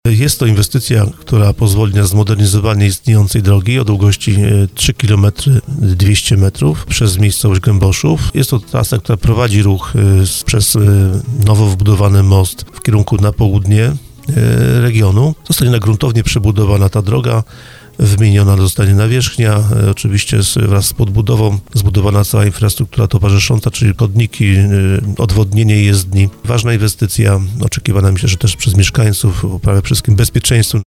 Mówił o tym w audycji Słowo za Słowo wiceprzewodniczący Sejmiku Województwa Małopolskiego Wojciech Skruch.